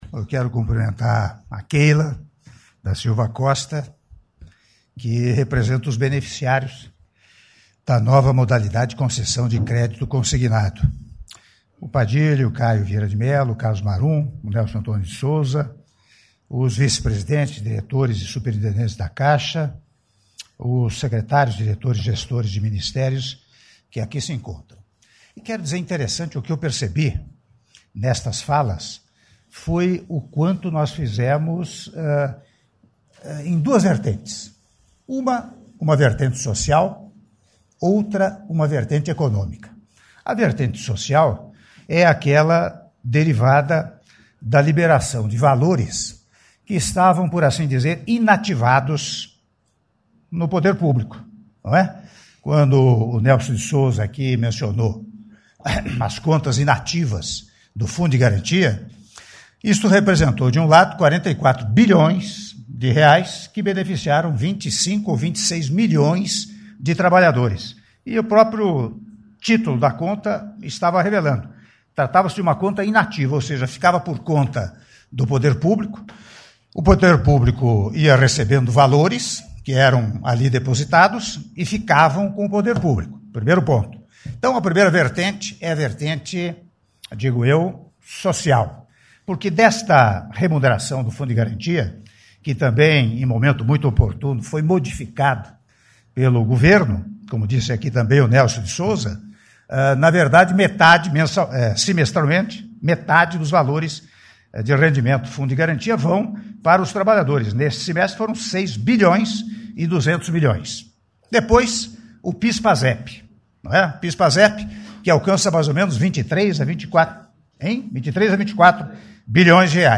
Áudio do Discurso do Presidente da República, Michel Temer, durante Cerimônia de Lançamento do Crédito Consignado ao Trabalhador Celetista - (07min)